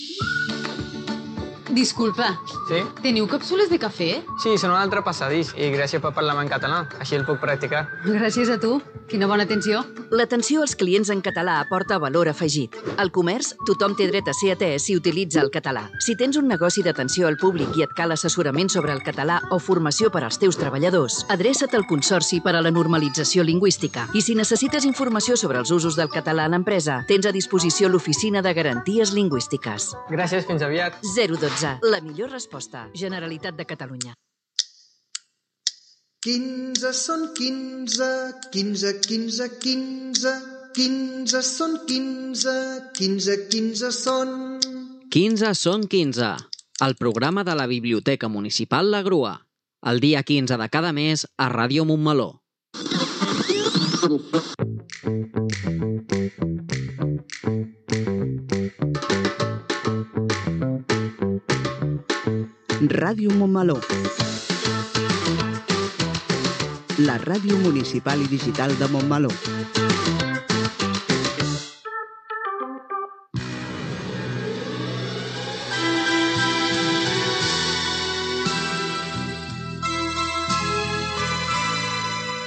Publicitat, promoció del programa "Quinze són quinze", indicatiu de l'emissora, tema musical